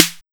SNARE6.wav